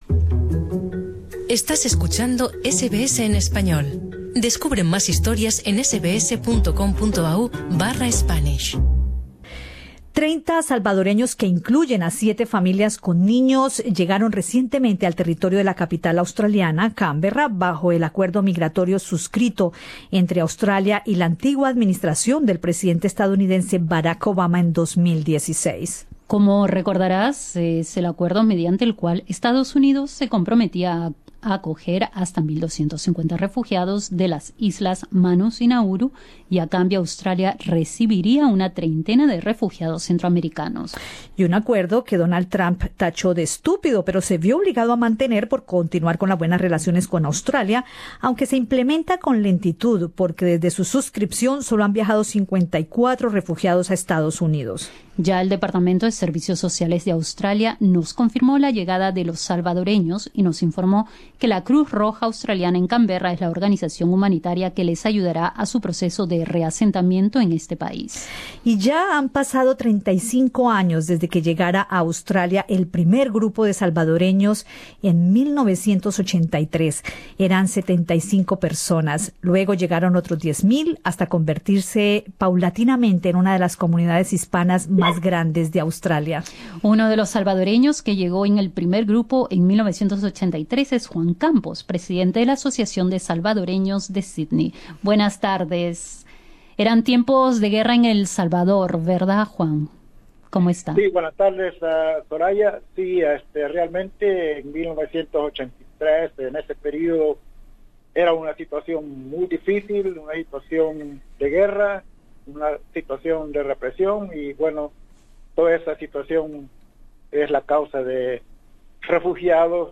En entrevista con Radio SBS